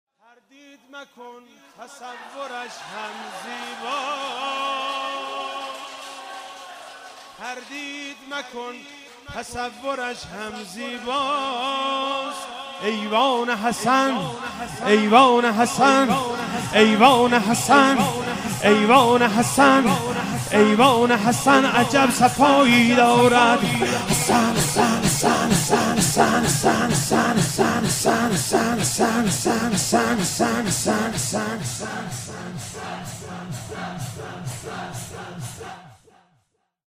شور - تردید مکن تصورش هم زیباست